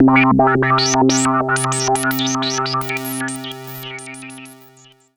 S AND H C3.wav